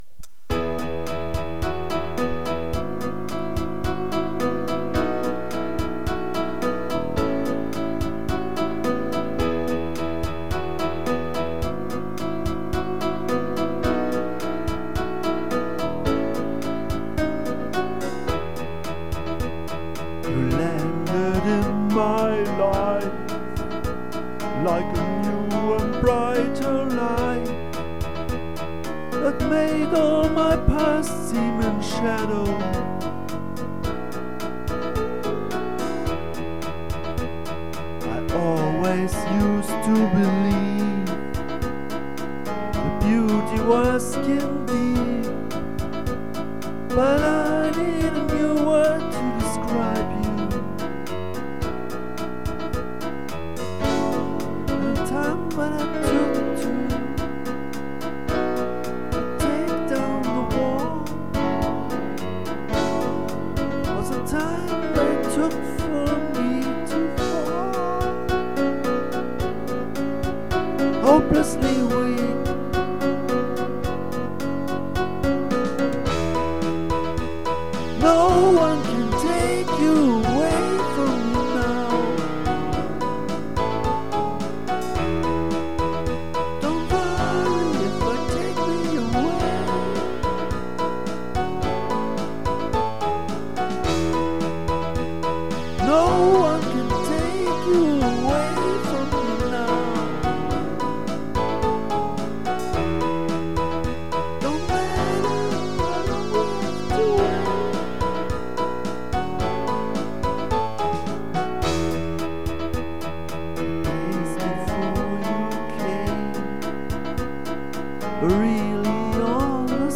8 of these songs were recorded with a karaoke program